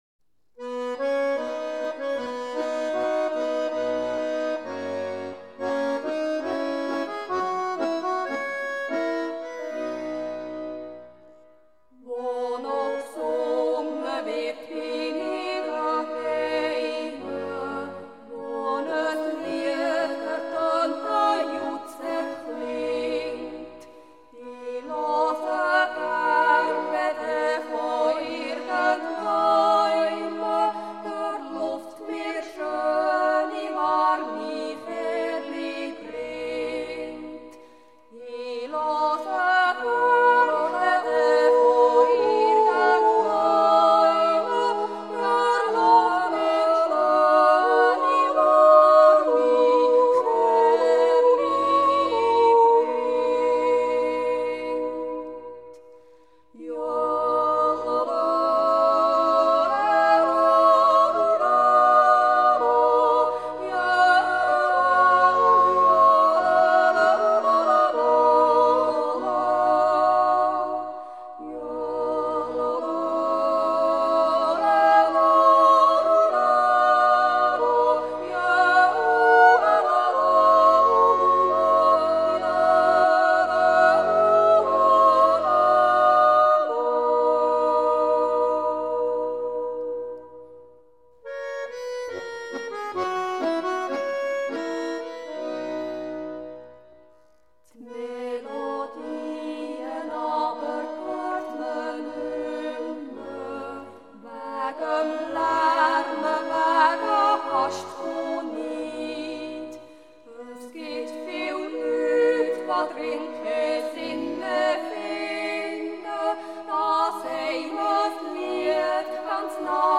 A Swiss National Yodeling Festival
Jodelduett